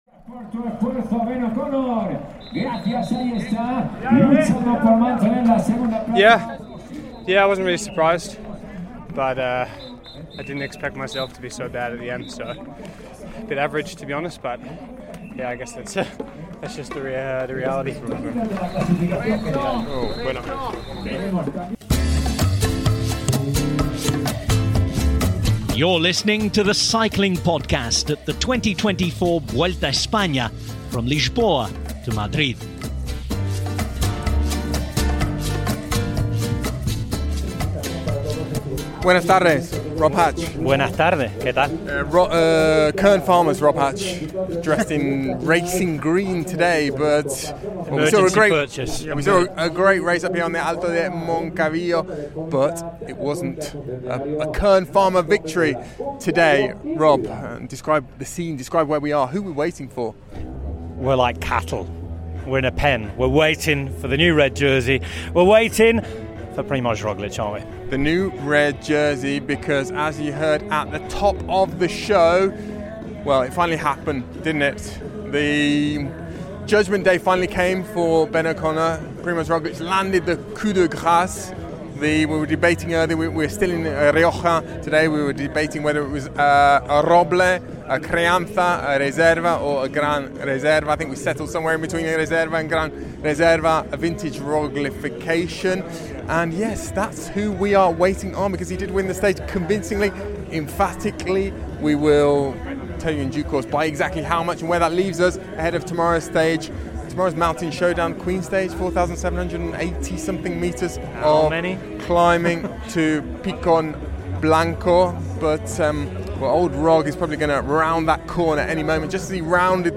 There’ll be analysis, interviews, wistful gazing and tepid takes from on the ground, in the thick of the action - and a return for both popular and unpopular features from previous editions!